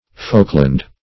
Folkland \Folk"land`\, n. [AS. folcland.] (O.Eng. Law)